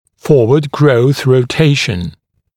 [‘fɔːwəd grəuθ rə’teɪʃ(ə)n][‘фо:уэд гроус рэ’тэйш(э)н]передняя ротация при росте